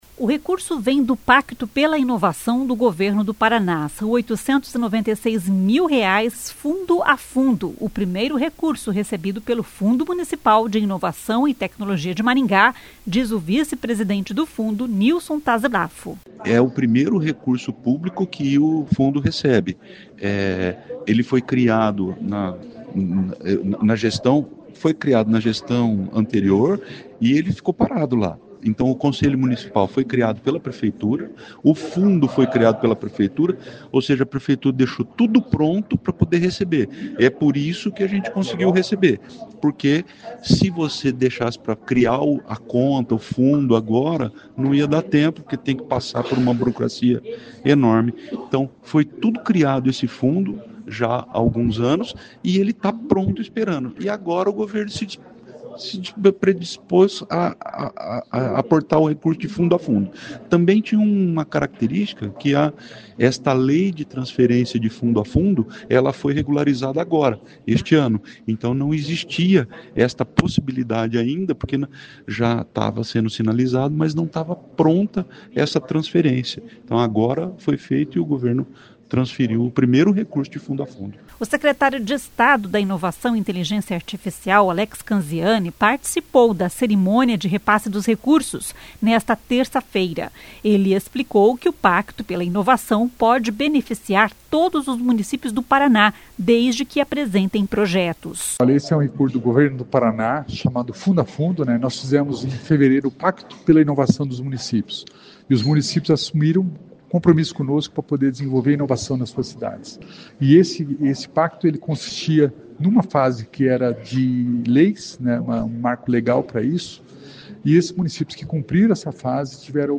O secretário de Estado da Inovação e Inteligência Artificial, Alex Canziani, participou da cerimônia de repasse dos recursos nesta terça-feira (30).
Com o dinheiro do Pacto pela Inovação, a Prefeitura de Maringá irá comprar equipamentos para instalar no Terminal Intermodal, um “Hub de Inovação” que irá oferecer ferramentas tecnológicas para qualquer cidadão com uma boa ideia, diz o prefeito Silvio Barros.